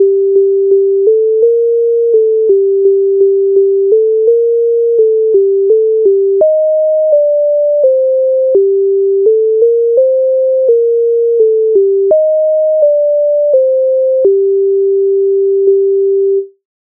MIDI файл завантажено в тональності g-moll
Котилася зірка Українська народна пісня з обробок Леонтовича с.96
Ukrainska_narodna_pisnia_Kotylasia_zirka.mp3